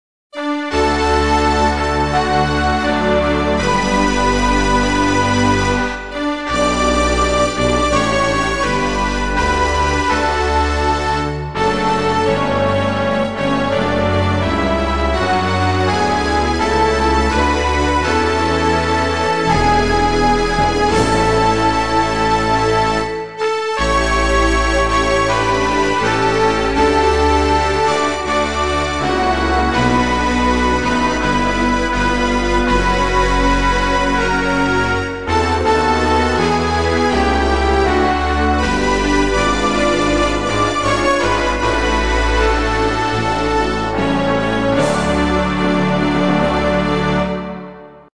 Inno nazionale